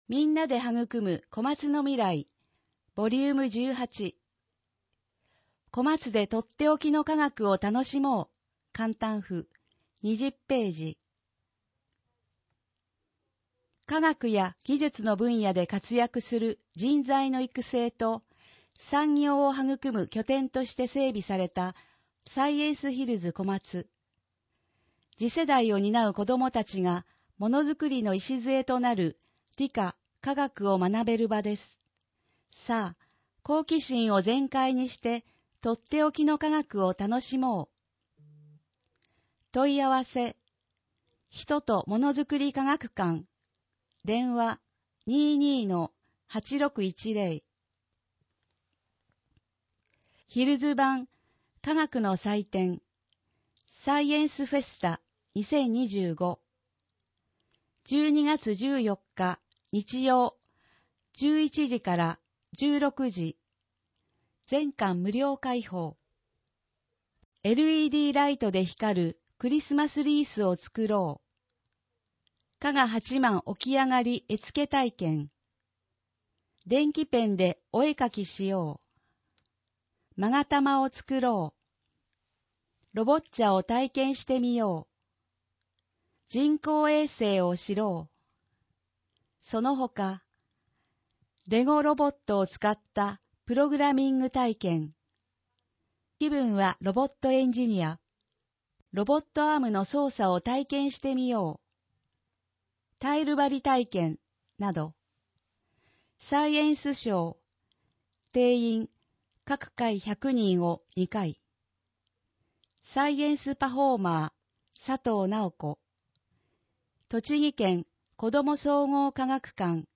広報こまつの音訳は音訳ボランティアグループ「陽だまり会」の皆さんの協力で行っています。